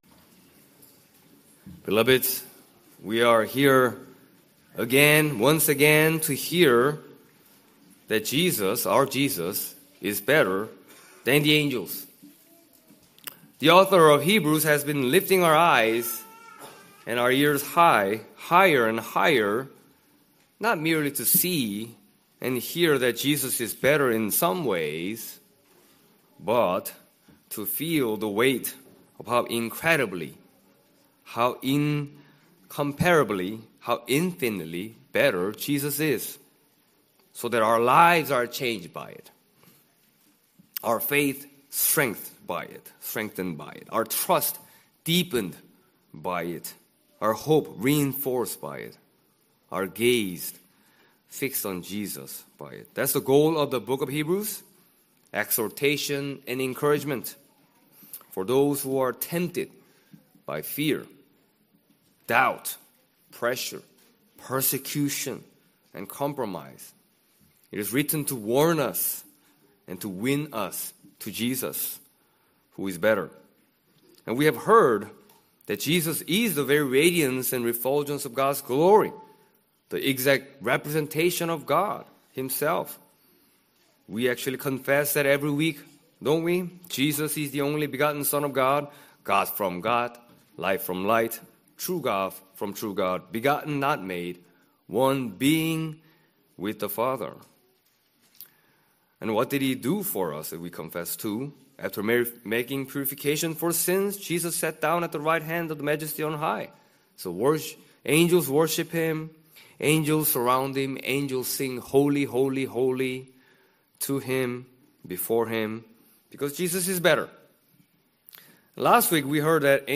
In this sermon on Hebrews 1:10-12